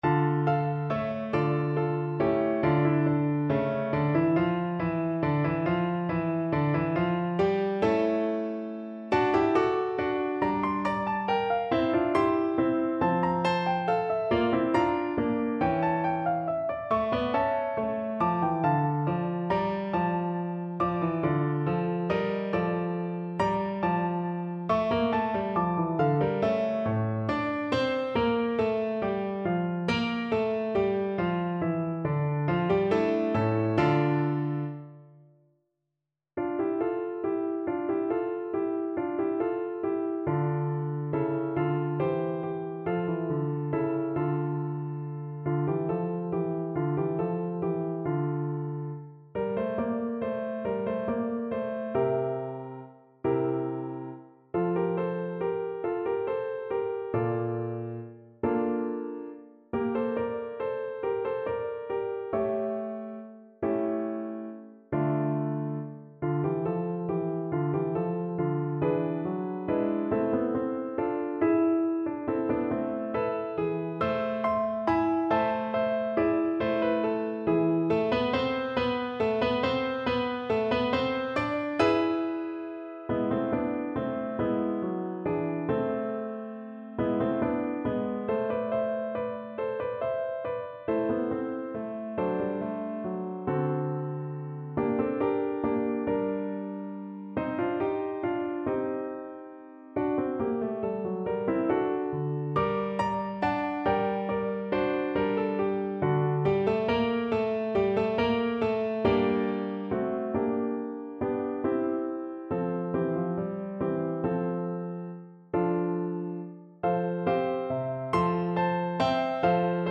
3/8 (View more 3/8 Music)
Classical (View more Classical French Horn Music)